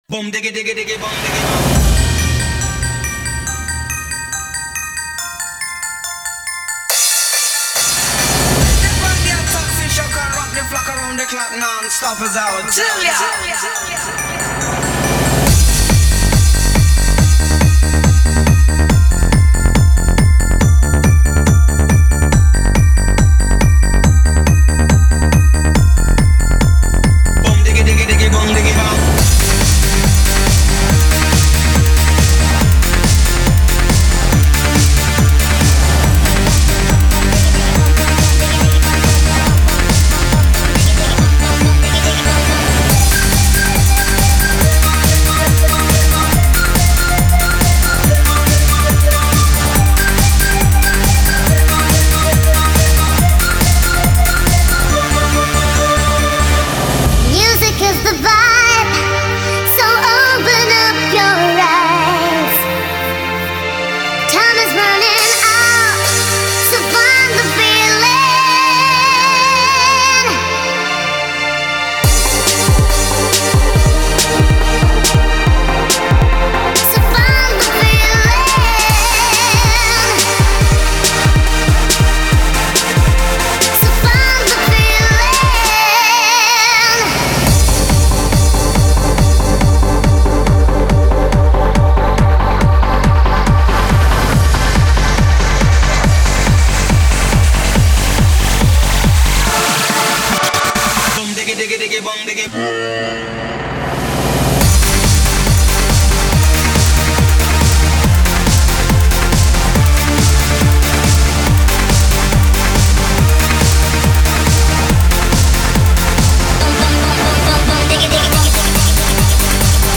(Ремикс)
Сэмплы: Свои + вокал